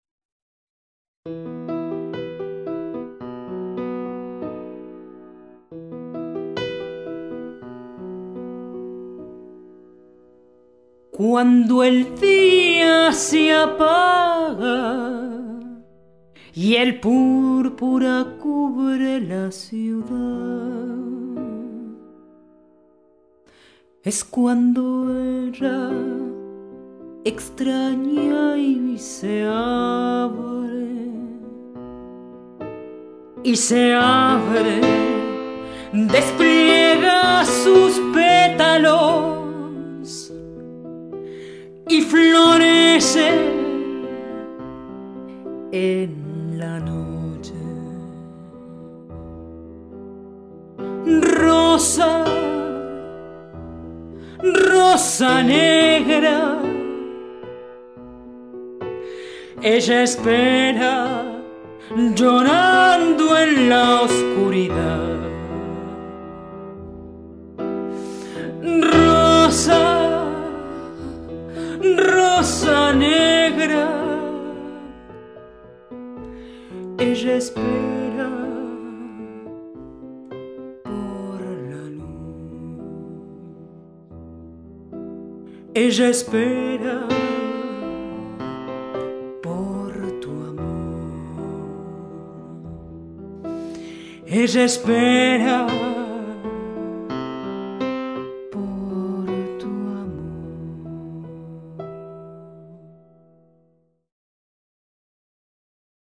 the second is a Spanish translation sun by tango singer